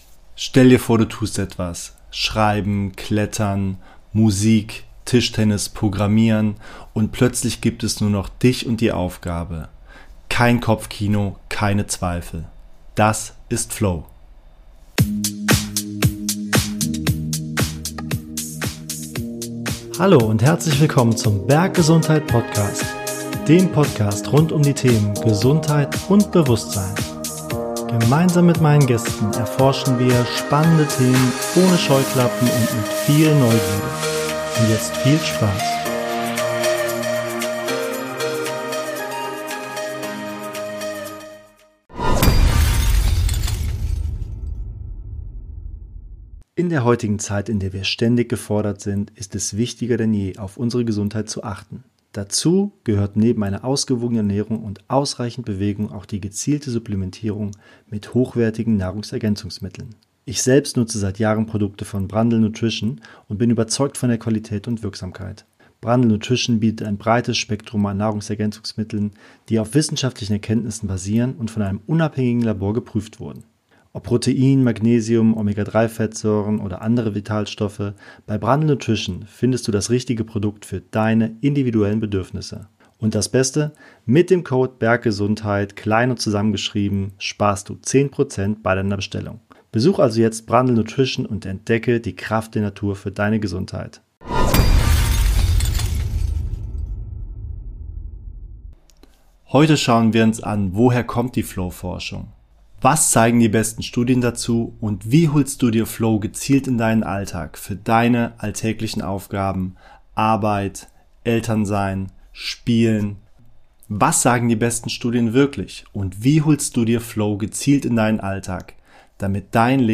In dieser Soloepisode erzähle ich die Geschichte der Flow-Forschung, stelle die spannendsten Studien vor und erkläre, warum Flow so eng mit Glück, Sinn und Wohlbefinden verbunden ist. Dazu gibt es alltagstaugliche Tipps, wie du Flow in Arbeit, Sport, Kreativität und Familienleben häufiger erleben kannst.